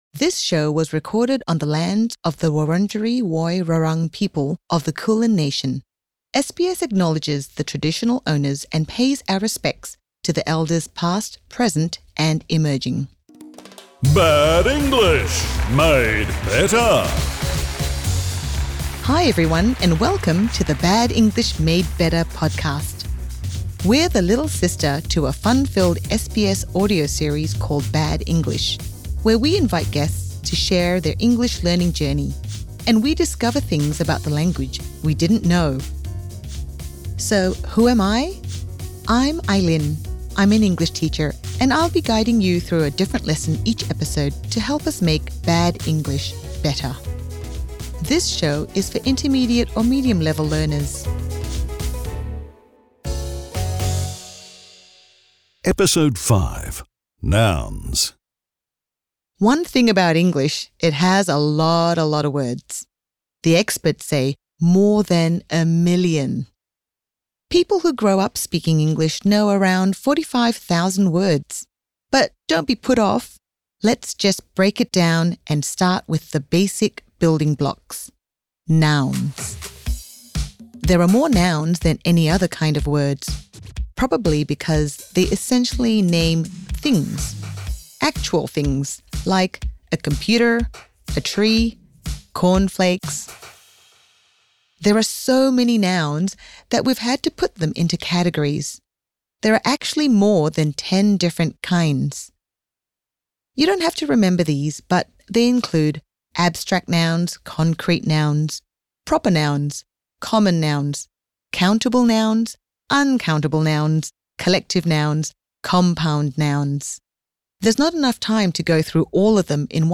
Learn about nouns and how to use measure words to count them. The 'Bad English Made Better' minipods are short and simple English grammar lessons.